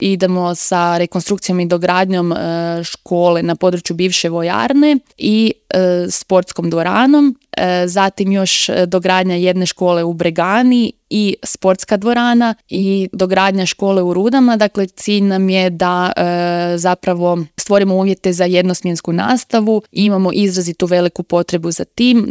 ZAGREB - U intervjuu Media servisa povodom rođendana grada Samobora gostovala je gradonačelnica Petra Škrobot.